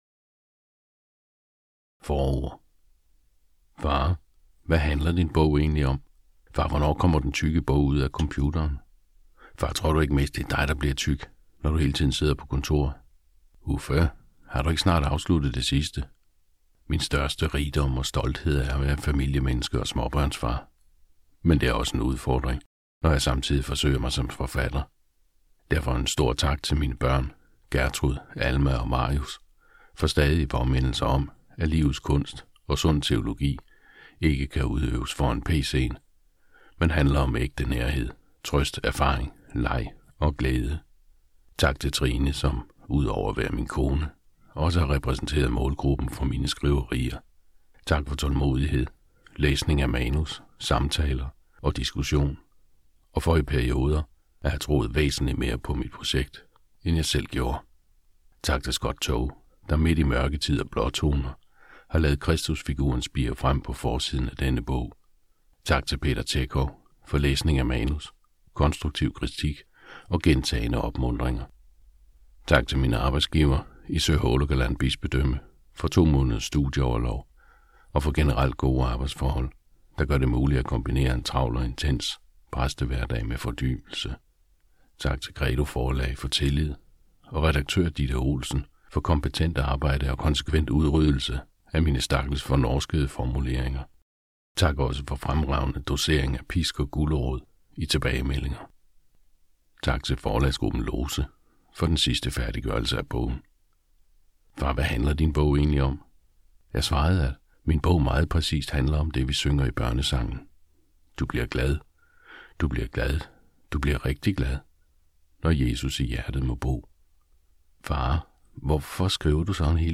Hør et uddrag af Kristusmystik Kristusmystik Om luthersk spiritualitet og helliggørelse Format MP3 Forfatter Uffe Kronborg Lydbog E-bog 149,95 kr.